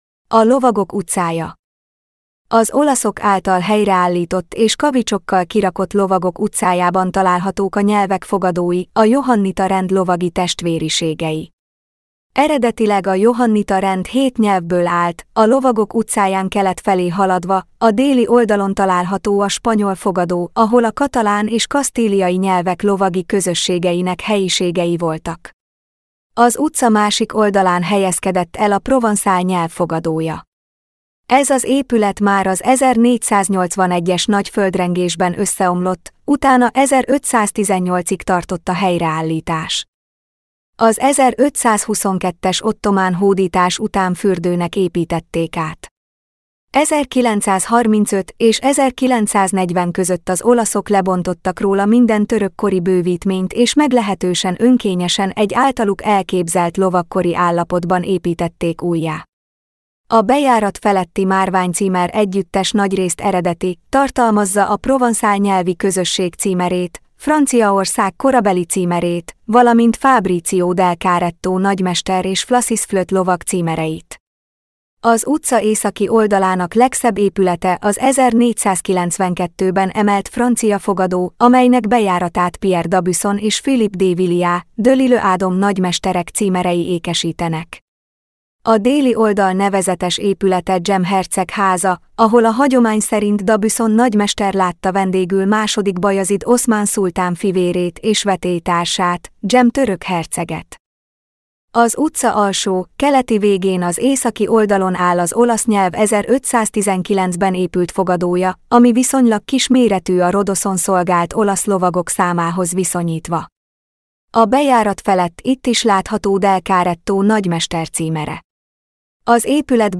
Felolvasom: